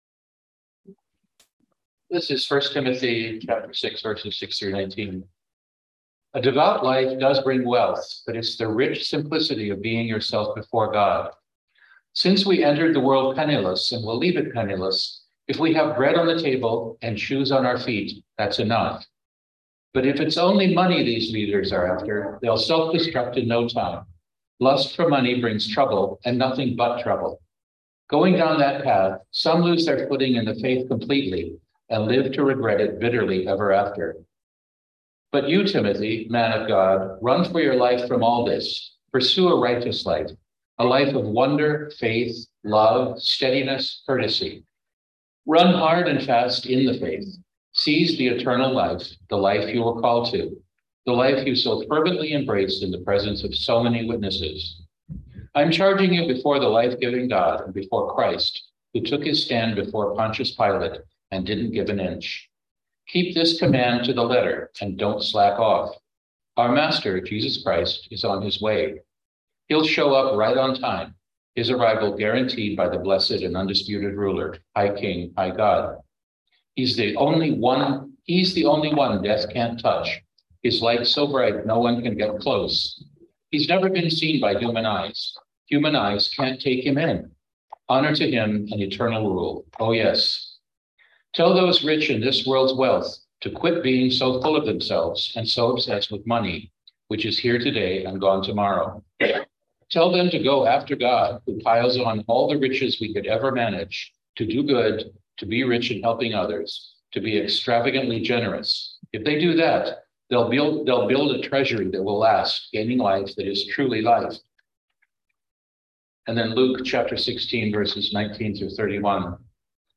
Listen to the most recent message from Sunday worship at Berkeley Friends Church, “The Love of Money.”